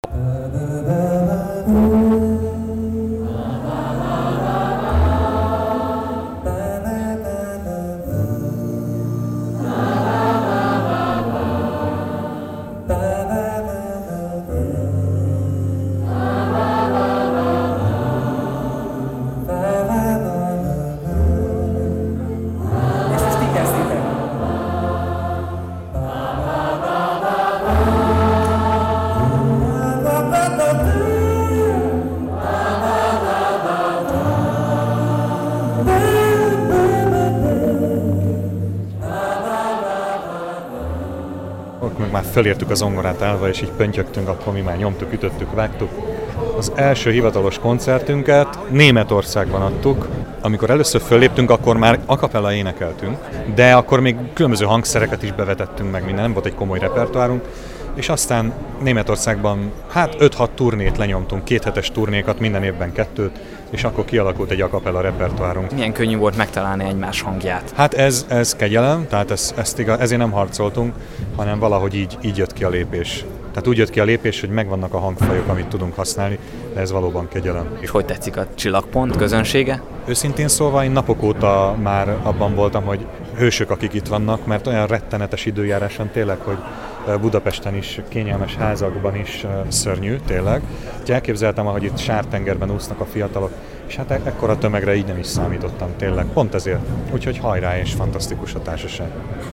A testvérek a közönséggel is énekeltek